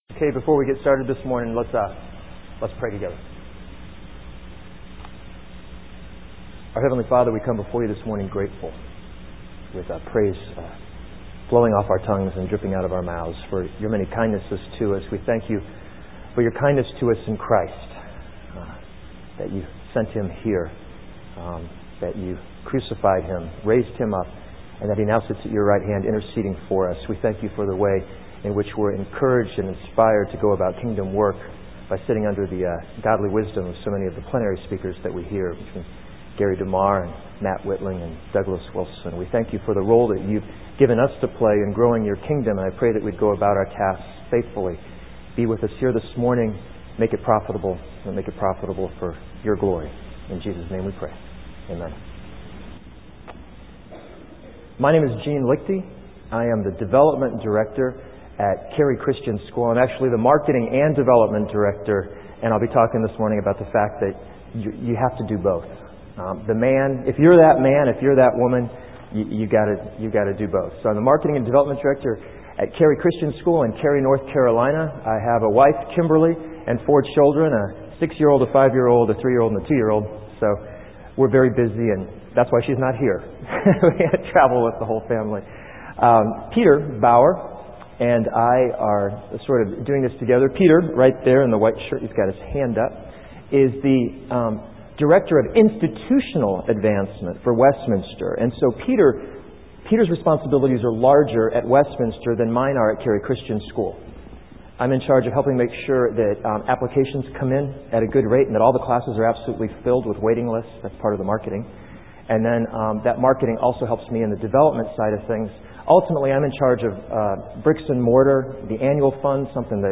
2005 Workshop Talk | 0:42:22 | Leadership & Strategic